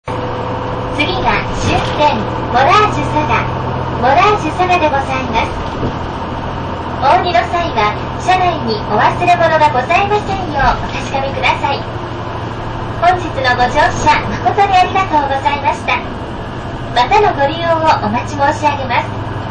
車内放送「